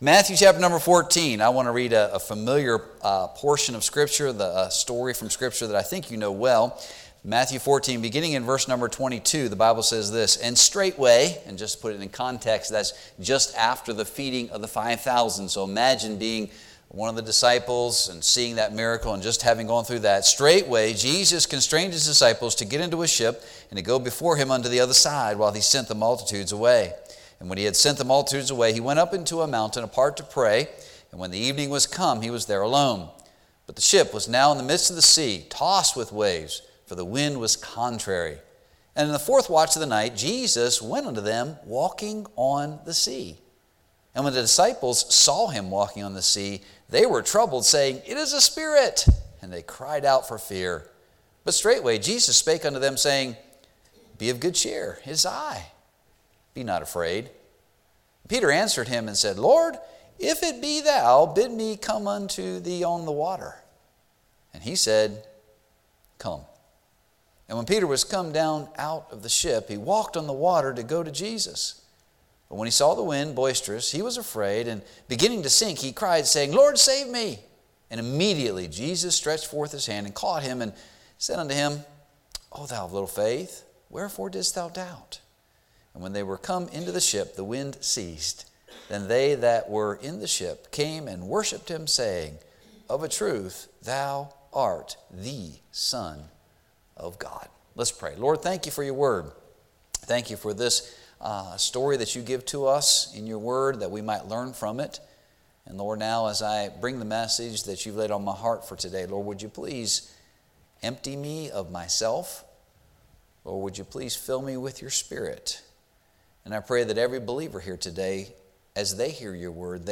Guest Speaker , Sunday Morning